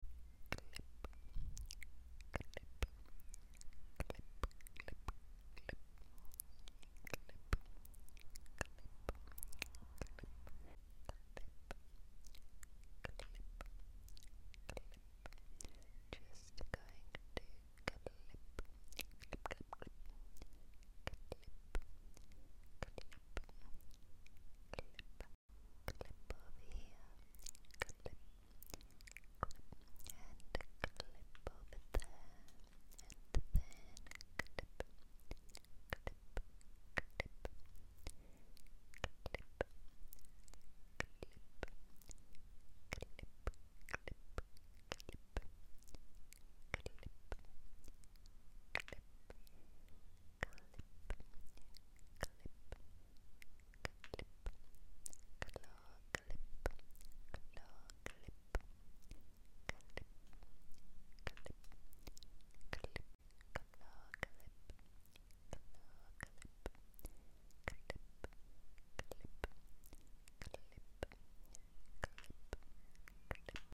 Clicky whispering claw clip triggers sound effects free download